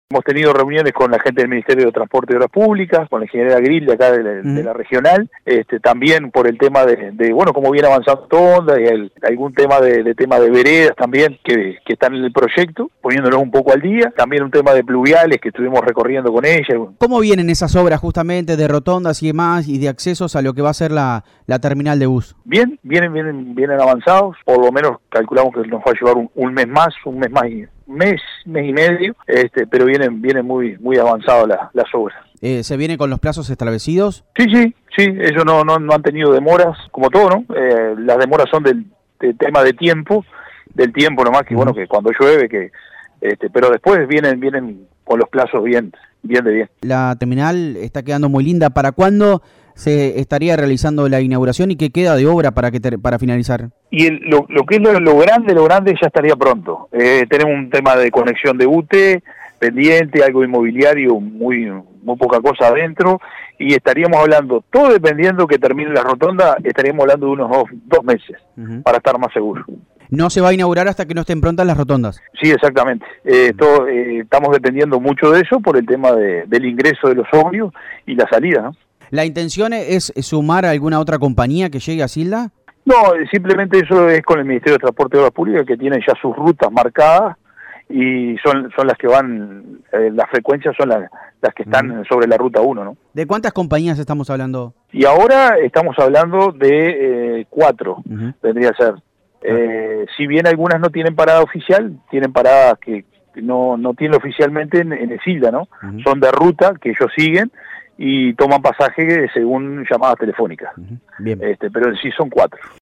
IGNACIO-MESA-TERMINAL.mp3